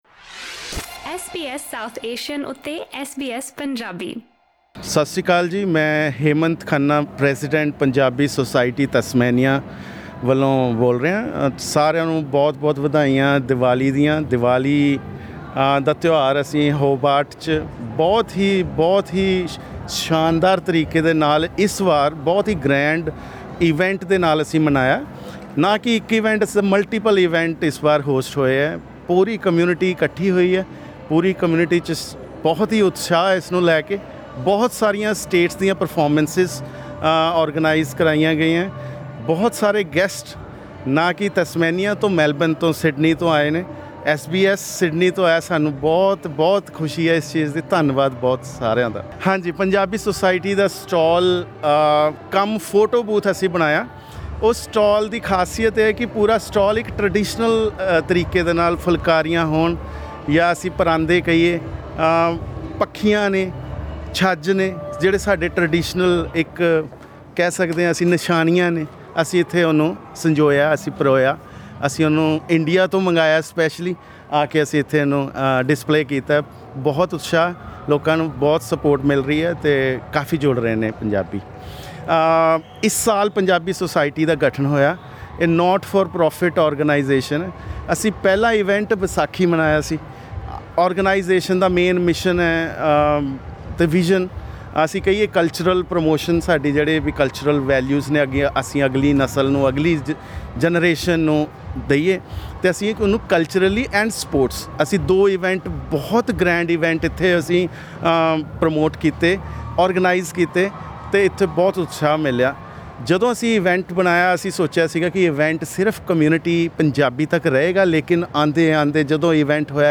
ਤਸਮਾਨੀਆ ਦੀ ਰਾਜਧਾਨੀ ਹੋਬਾਰਟ ਵਿੱਚ ਕਰਵਾਏ ਗਏ ਦੀਵਾਲੀ ਮੇਲੇ ਮੌਕੇ ਐਸਬੀਐਸ ਦੀ ਟੀਮ ਨਾਲ ਗੱਲਬਾਤ ਕਰਦੇ ਹੋਏ